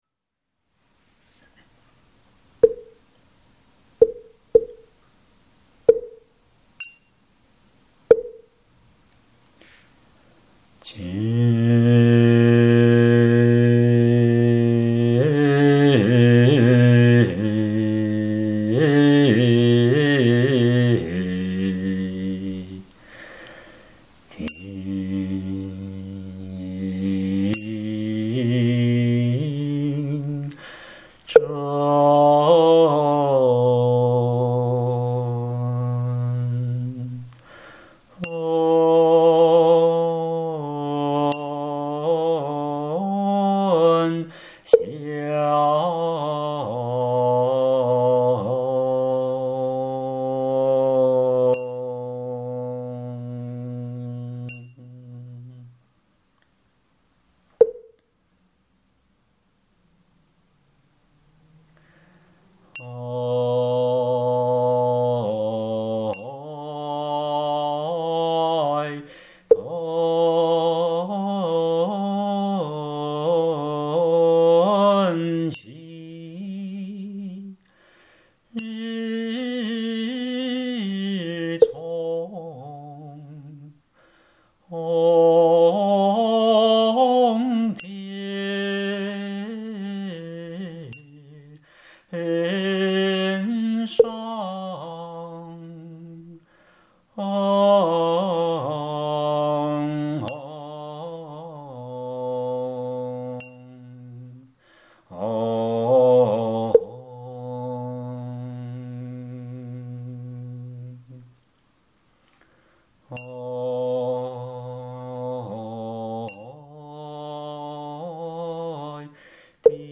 5、《新蒙山施食》梵呗仪轨及教学音档 佛教正觉同修会_如来藏网